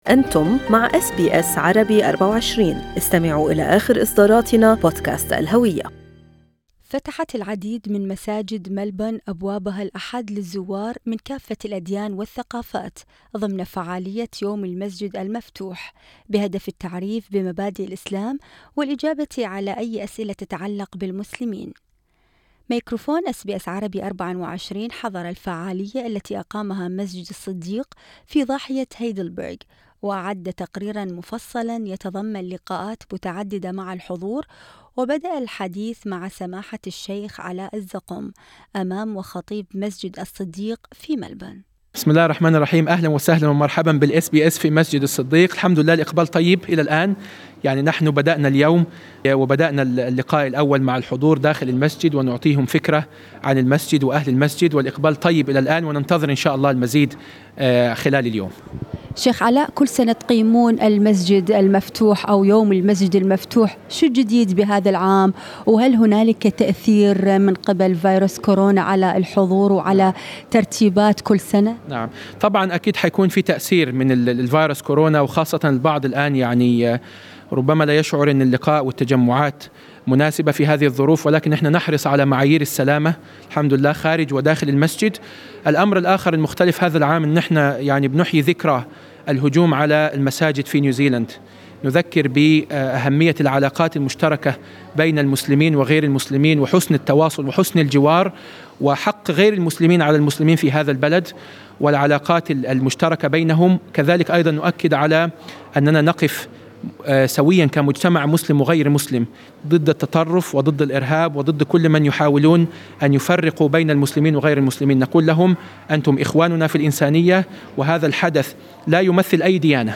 فتحت العديد من مساجد ملبورن أبوابها الأحد للزوار من كافة الأديان والثقافات ضمن فعالية "يوم المسجد المفتوح" بهدف التعريف بمبادئ الإسلام والإجابة على أي اسئلة تتعلق بالمسلمين.